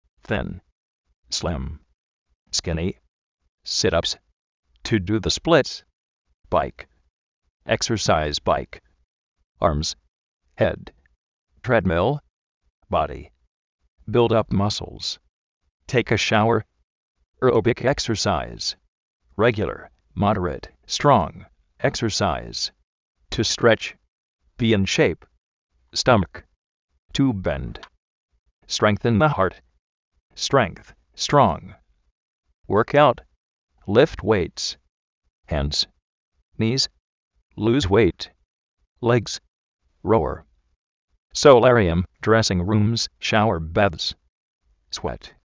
zín
slím
skíni
siráps
trédmil
réguiular (módereit, stróng) éksersais